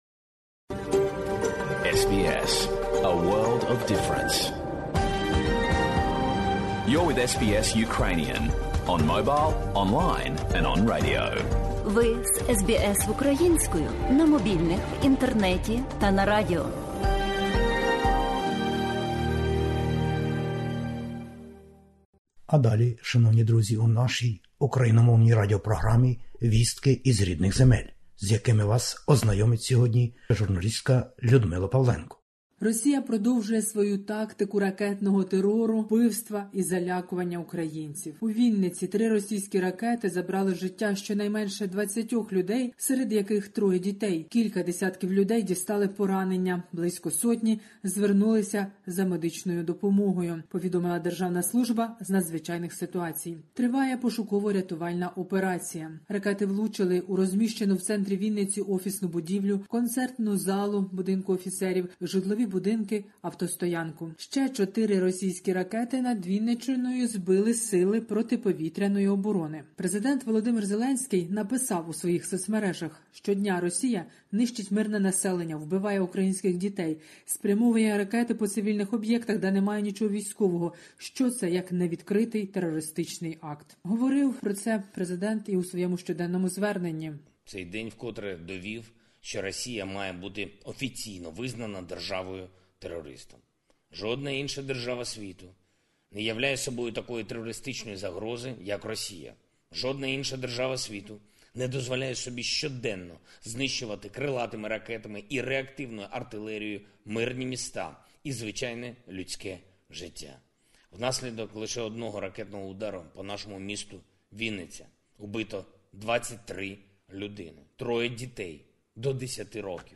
Добірка новин із героїчної України.